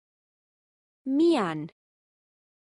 Amazon AWS (pronunciation):